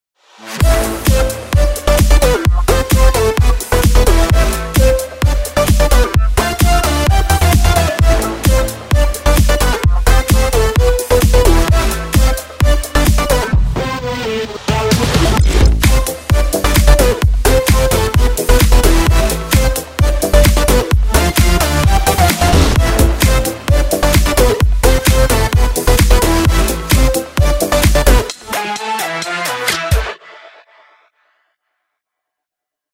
דרופ קצר שלי!!
הסאונד טוב בכללותו אבל אפשר לפתוח קצת גבוהים ולהוריד תדרים שלא נצרכים